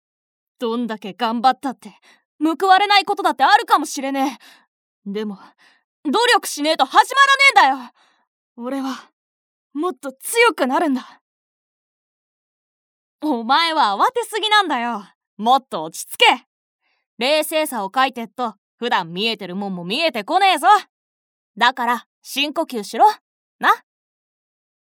ナレーション01